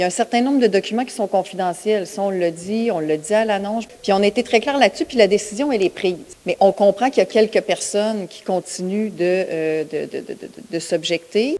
Selon un extrait tiré du reportage de TVA Nouvelles, Geneviève Guilbault a même fait une déclaration qui pourrait mettre le feu aux poudres. La ministre mentionne que « quelques personnes » continuent de s’objecter à la décision de transférer la traverse à Cacouna.